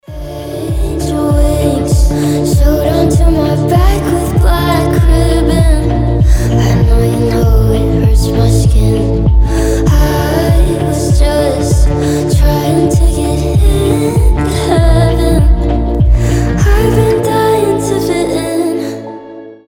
• Качество: 320, Stereo
медленные
красивый женский голос